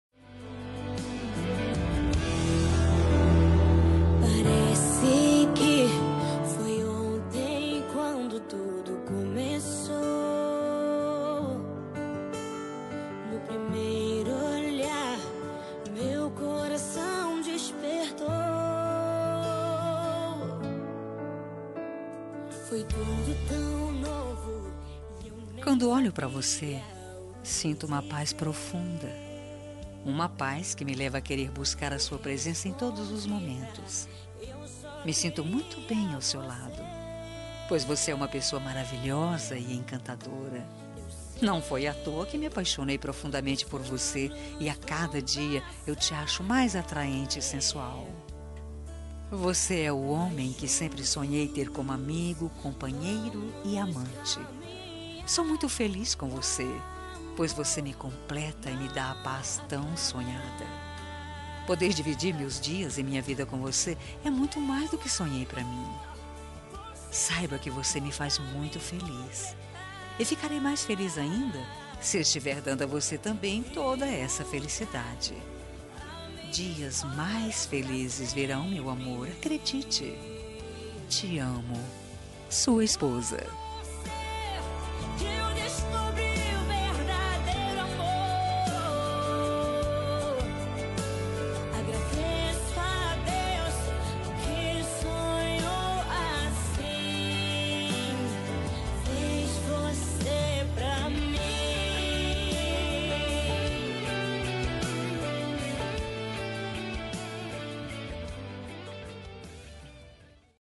Telemensagem Romântica Para Marido – Voz Feminina – Cód: 7852